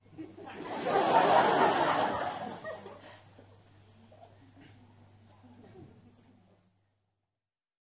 剧院 " 笑4
描述：LaughLaugh在中型剧院用MD和索尼麦克风录制，在人的上方
Tag: 听众 礼堂 人群 捷克 布拉格 戏剧